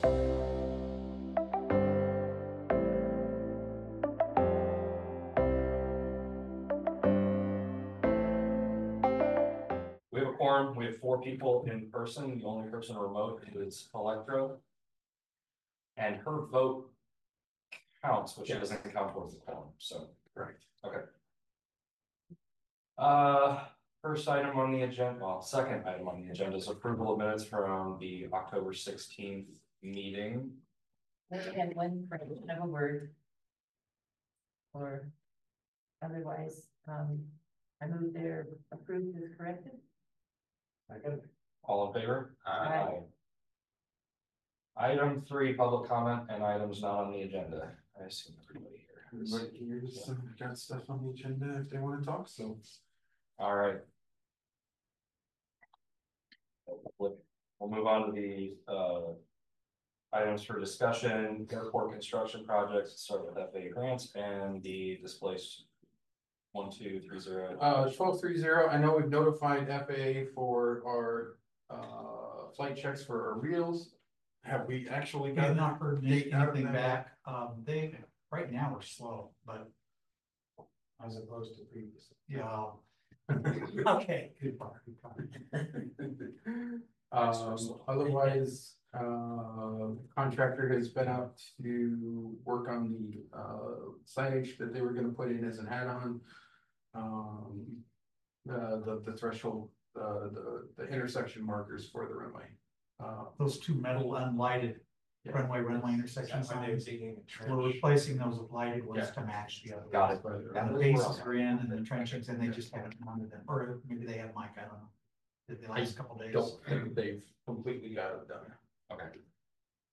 Regular monthly meeting of the City of Iowa City's Airport Commission.